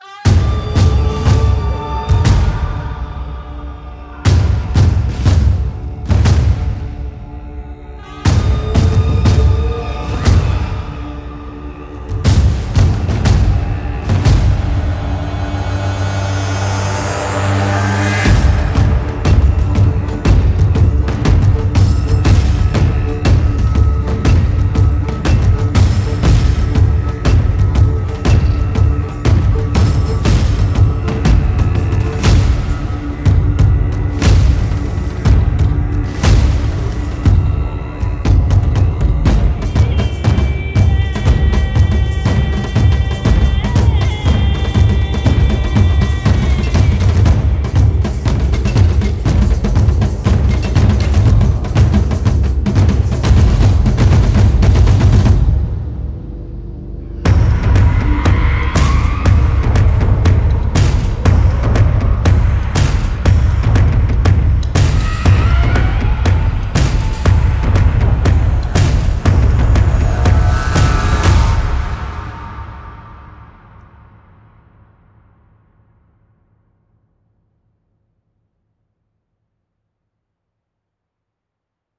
Cartaqo 是一款 Kontakt 音色库，其核心在于丰富的古代、现代以及定制的电影打击乐音色。
收录了种类繁多的打击乐器，包括原声乐器和定制乐器，从经典的管弦乐低音鼓、定音鼓和小军鼓，到定制的罗马定音鼓和各种框鼓，应有尽有。
此外，Cartaqo 还囊括了众多传统世界乐器，例如日本太鼓、中东达布卡鼓和西非杰姆贝鼓，以及一些罕见的自鸣乐器，如响板、铃铛、西斯特鲁姆鼓和斯卡贝鲁姆鼓。
Cartaqo 由两个功能强大的乐器组成：原声打击乐音序器和定制鼓组。